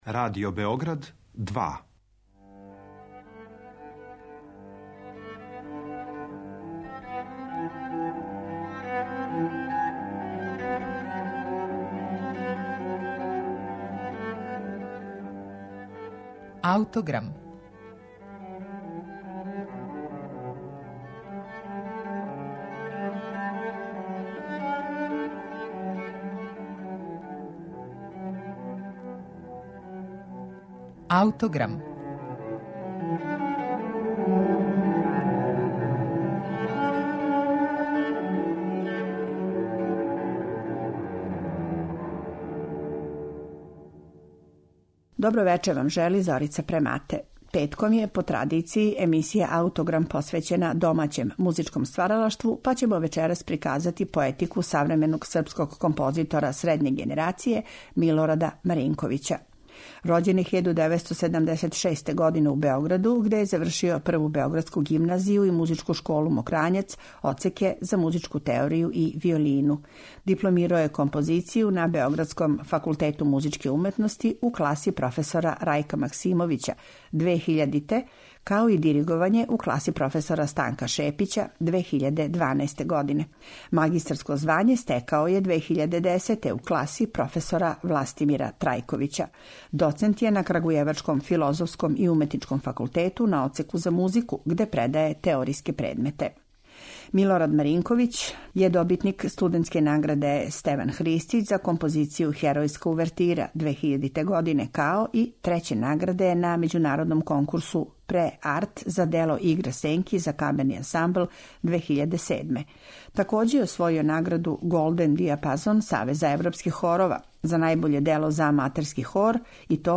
необична камерна композиција